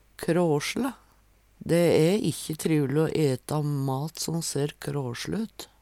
kråsle - Numedalsmål (en-US)